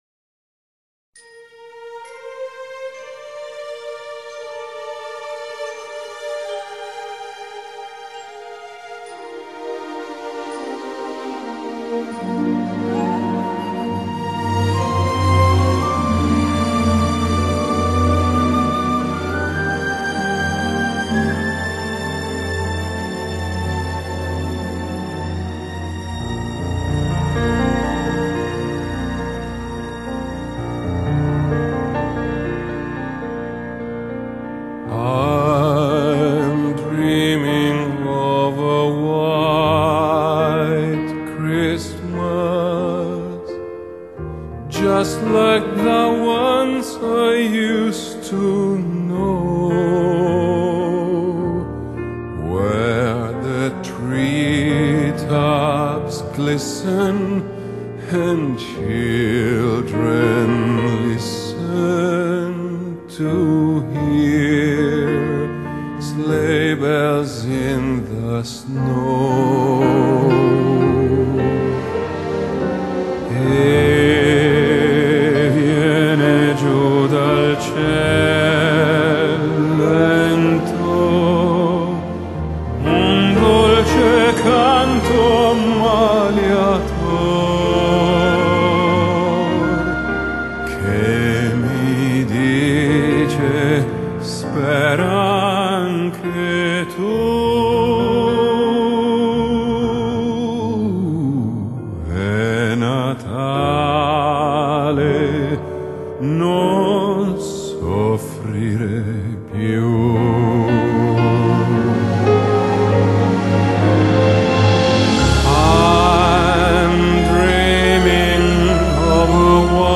Genre: Classic, Opera